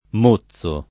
mozzo [ m 1ZZ o ]